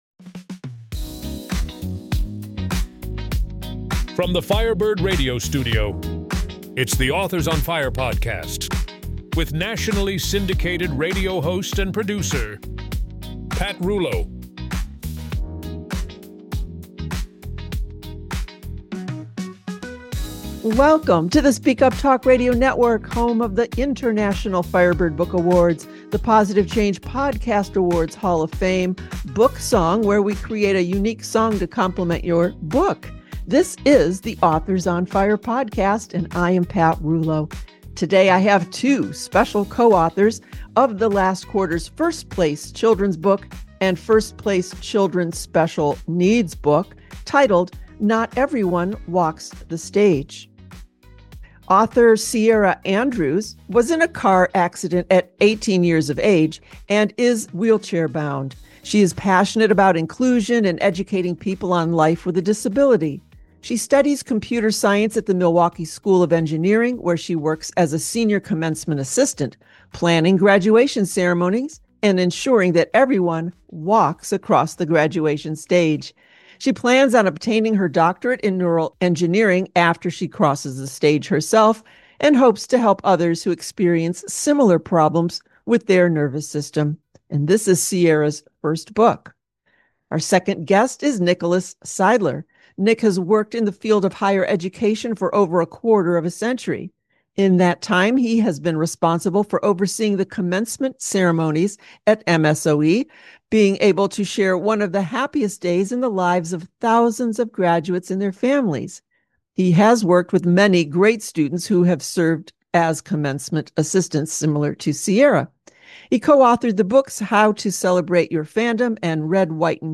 Interview NOT EVERYONE WALKS THE STAGE